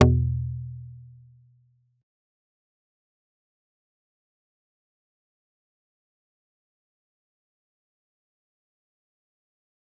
G_Kalimba-E2-pp.wav